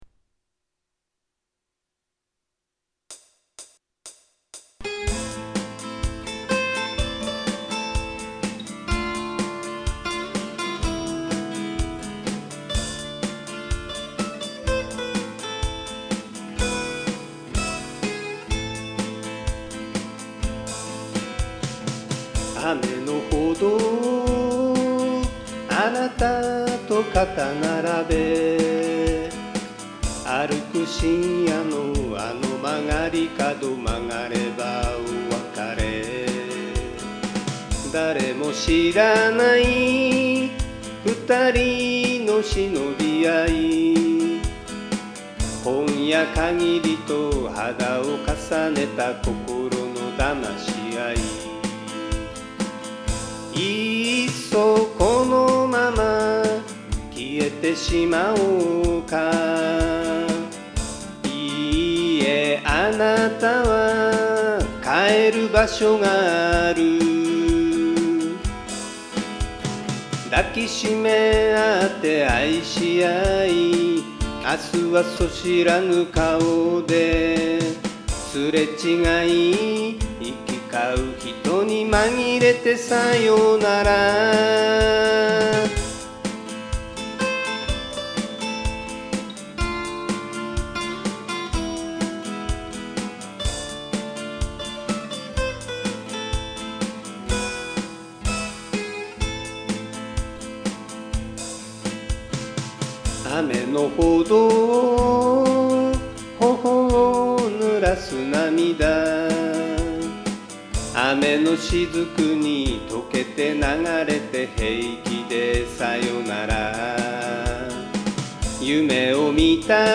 視聴（イントロギターリード入り）
ドラムが出来上がったら、ギターのストロークとアルペジオを録音。
10回以上録音しなおしてもどこかで間違えるのであきらめて、イントロのリードギターを録音。
ベース以外の音とドラムの音を重ねて伴奏が完成。
少しテンポがずれますが、何とか修正が終わってやっと完成。
ベースギターと、歌の中のリードギターを入れたいけど、このままで原曲完成です。。